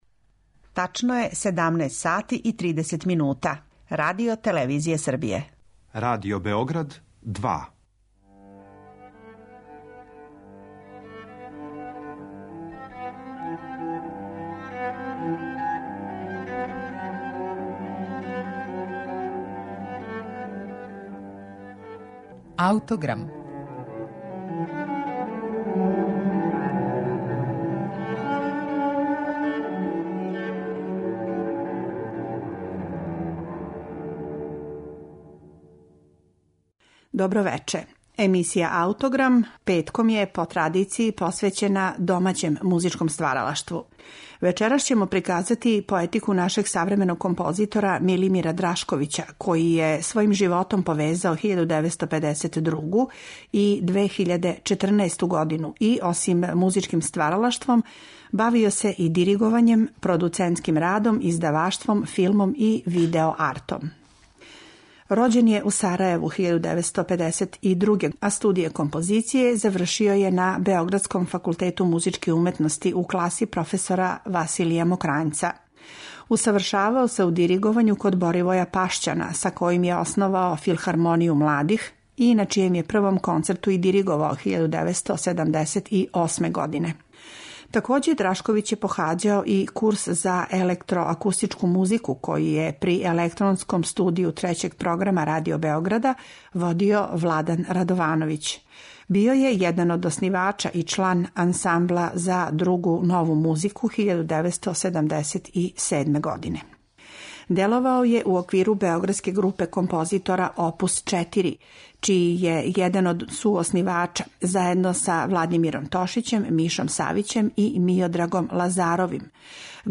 Биће емитована обимна Драшковићева композиција „Осам недеља", инспирисана модалним напевима и линеарношћу српског „Осмогласника".